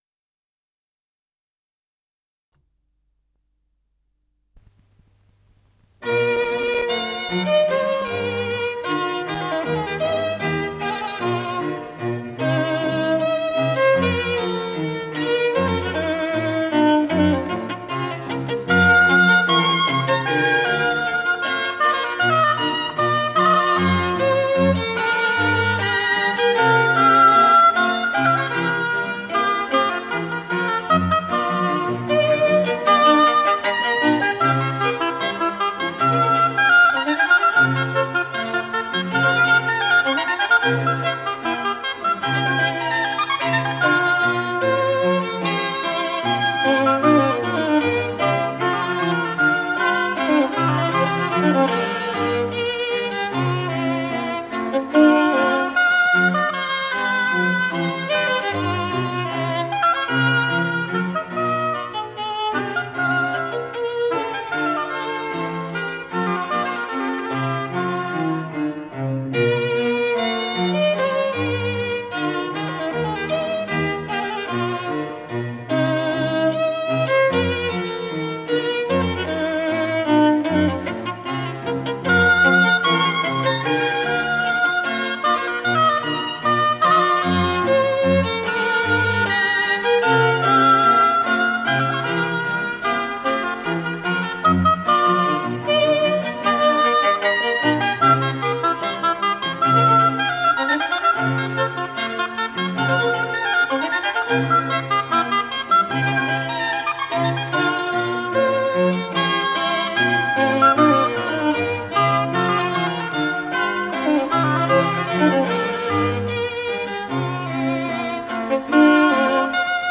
с профессиональной магнитной ленты
Аллегро ма нон троппо
ИсполнителиКамерный ансамбль "Барокко"
гобой
скрипка
клавесин
виолончель
ВариантДубль моно